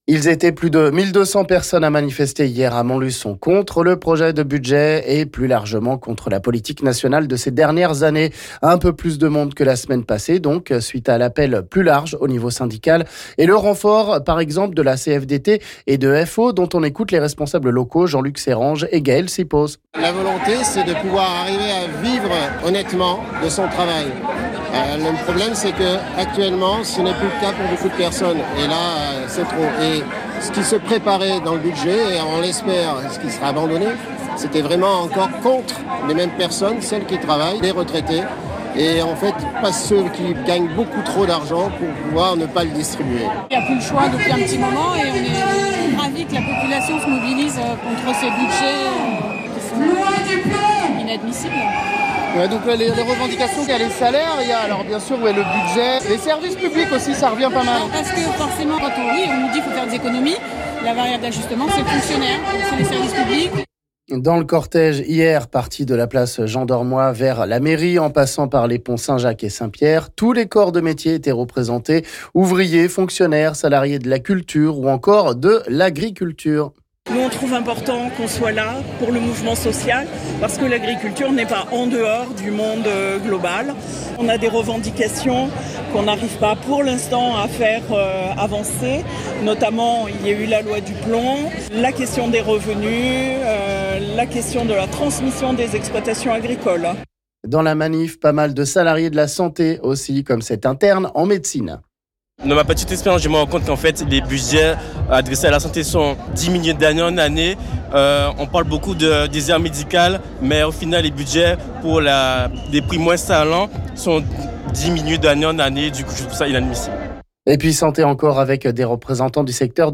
Un peu plus de monde que la semaine paséee hier dans la manifestation à Montluçon